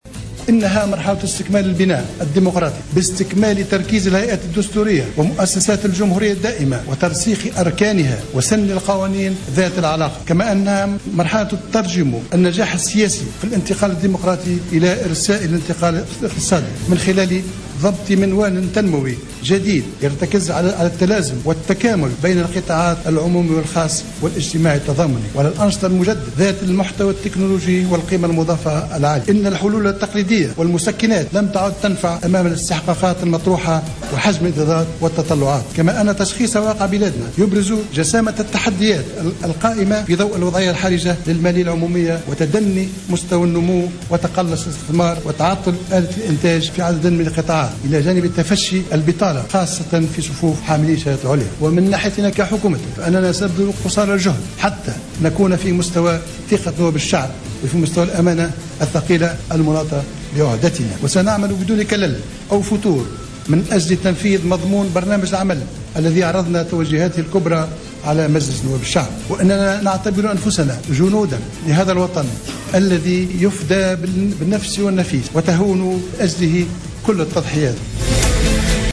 أكد رئيس الحكومة الجديد الحبيب الصيد اليوم الجمعة في كلمة خلال موكب تسلّم حكومته لمهامها أن المرحلة القادمة ستكون مرحلة استكمال البناء الديمقراطي باستكمال تركيز الهيئات الدستورية و مؤسسات الجمهورية الدائمة.